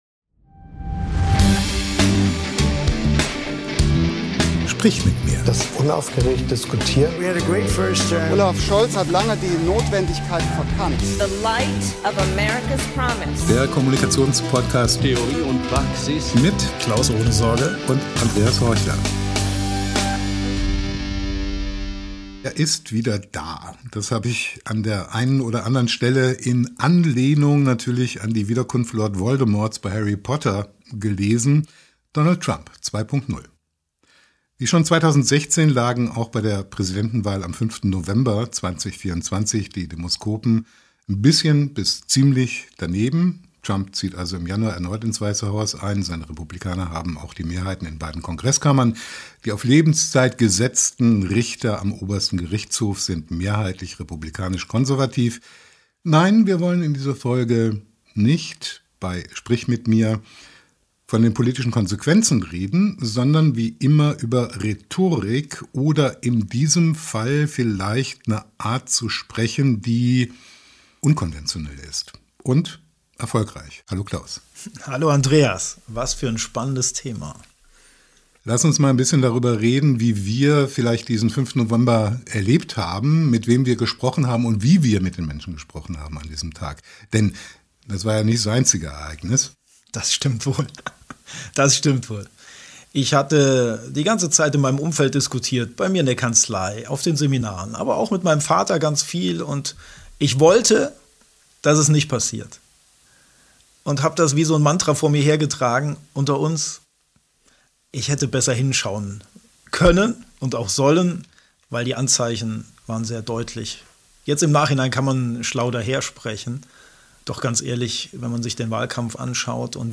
Die beiden Cousins sprechen in dieser Folge darüber, wie wir rhetorisch auf unsere politisch problematische Umwelt reagieren können.